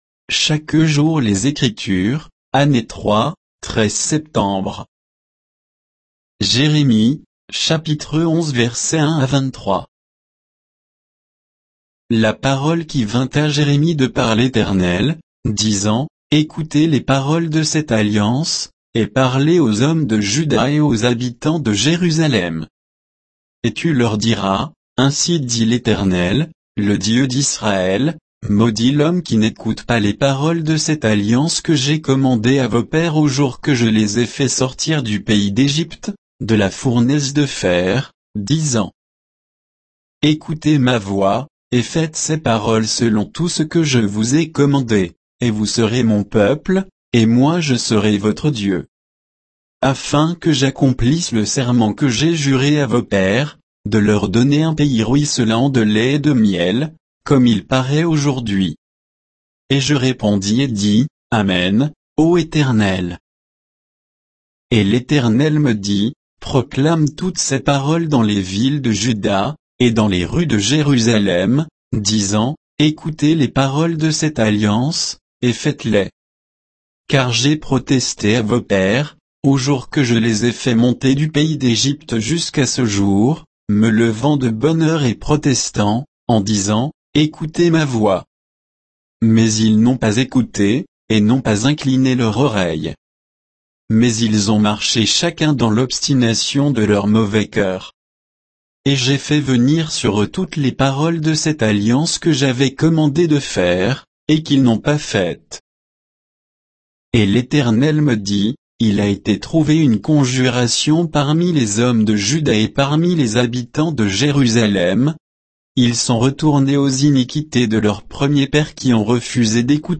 Méditation quoditienne de Chaque jour les Écritures sur Jérémie 11, 1 à 23